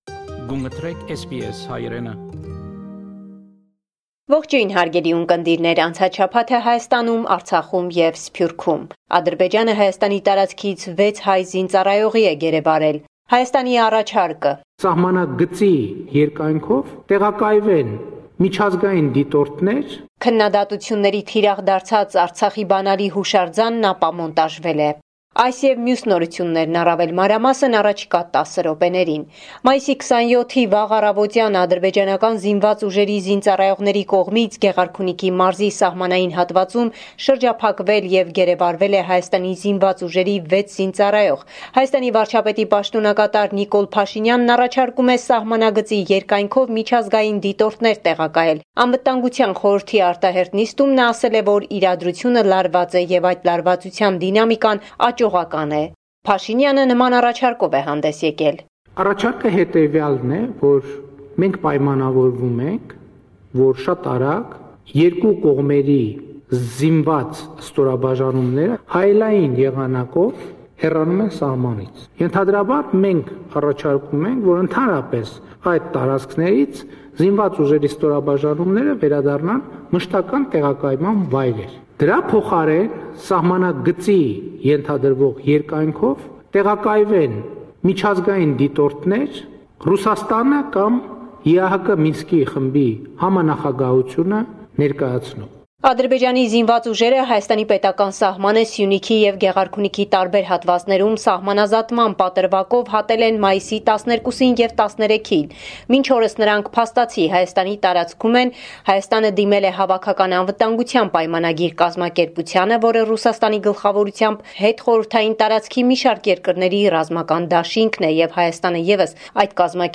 Latest News from Armenia – 1 June 2021